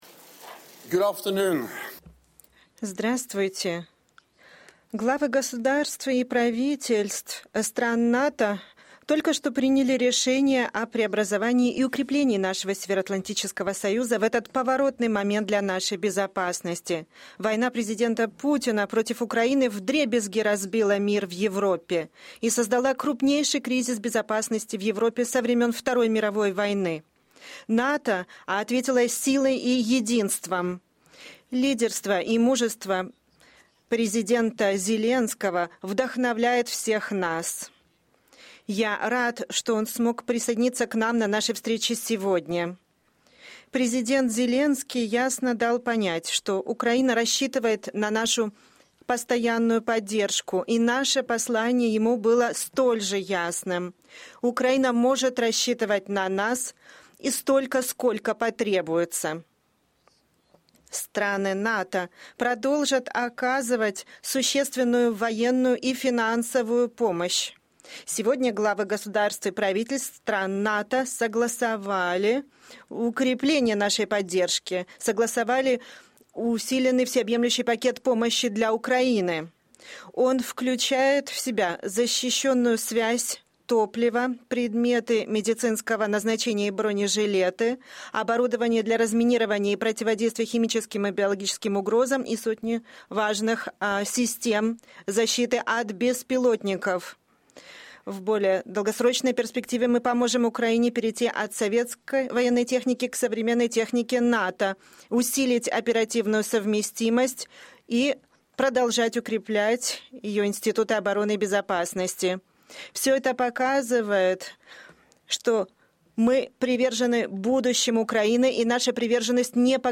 Press conference by NATO Secretary General Jens Stoltenberg following the meeting of the North Atlantic Council at the level of Heads of State and Government (2022 NATO Summit)